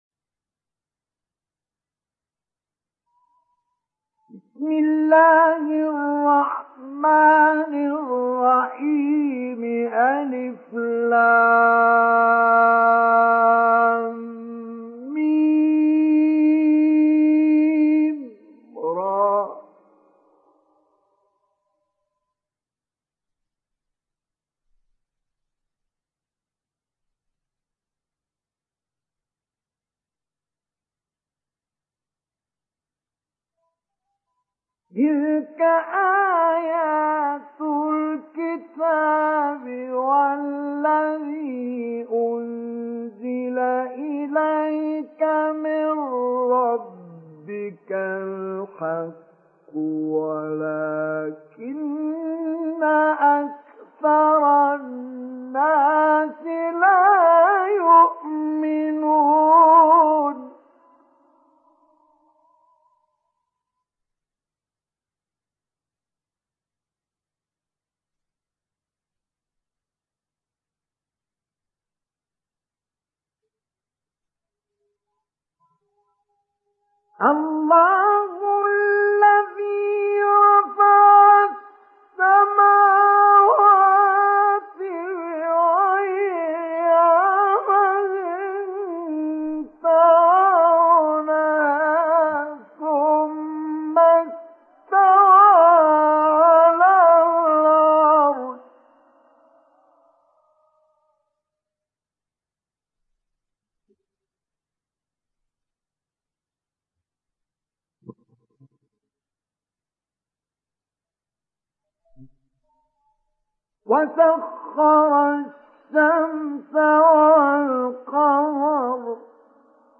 Surah Ar Rad Download mp3 Mustafa Ismail Mujawwad Riwayat Hafs from Asim, Download Quran and listen mp3 full direct links
Download Surah Ar Rad Mustafa Ismail Mujawwad